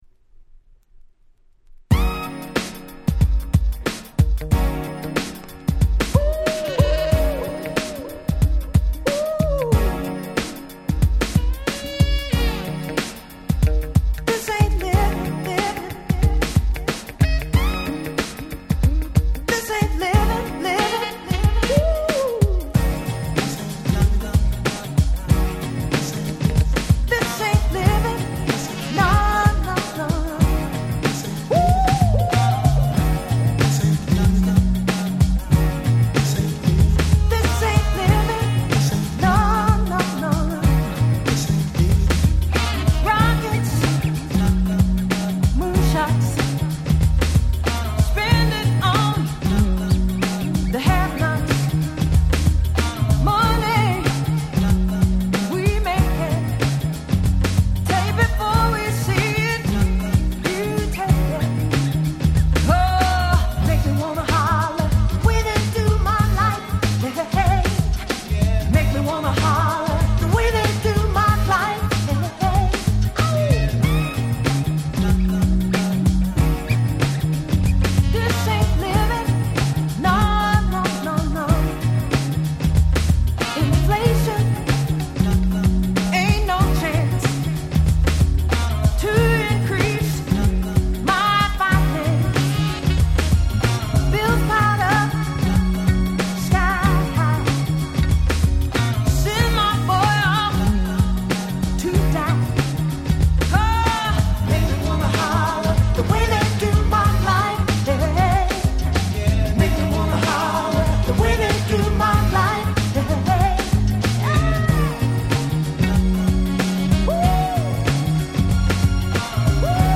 94' Nice Cover R&B !!